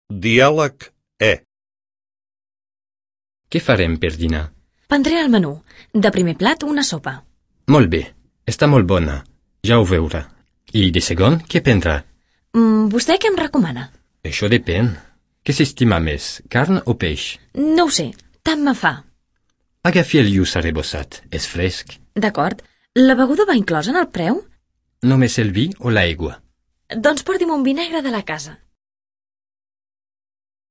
Diàleg E
Dialeg-E-el-menjar.mp3